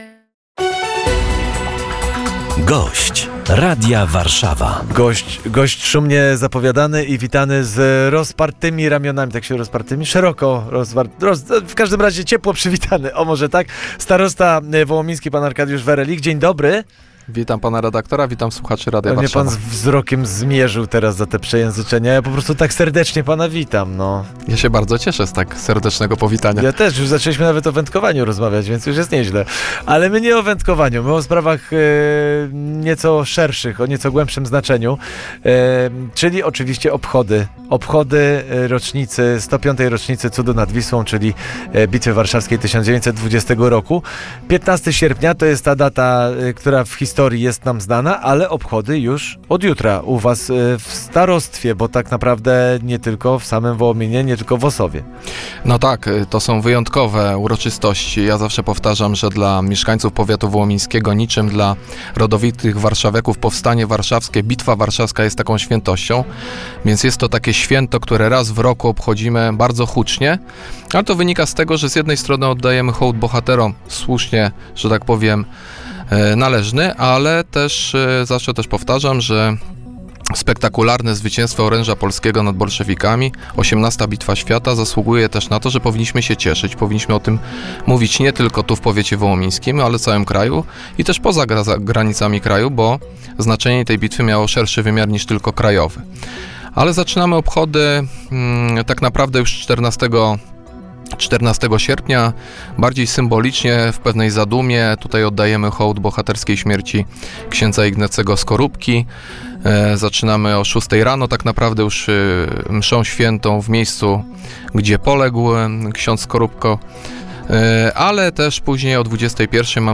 Zapraszał na nie na antenie Radia Warszawa starosta powiatu wołomińskiego Arkadiusz Werelich.